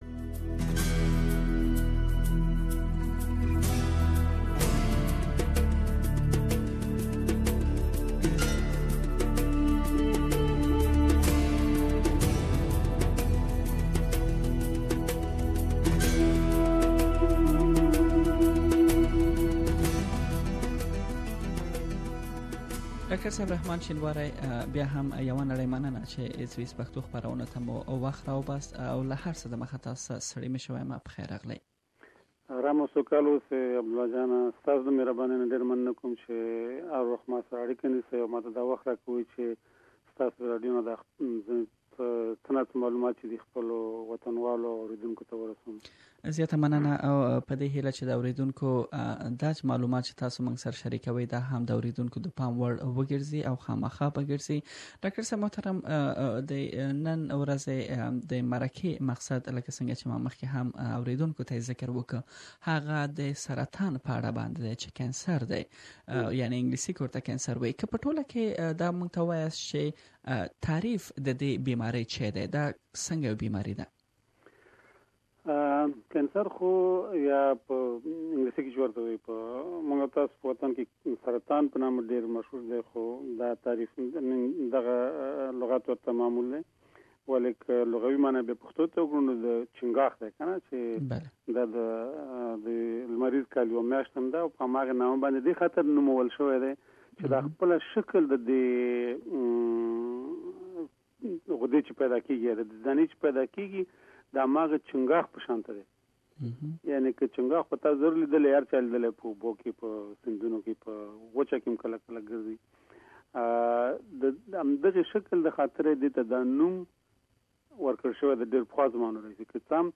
He has some interesing information to share, Please listen to the first part of this interview.